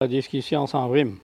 parole, oralité
Catégorie Locution